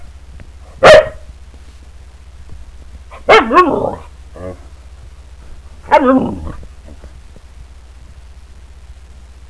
Miss Ketah, the Siberian Husky
Huskies are very vocal.
But most of the time I just howl.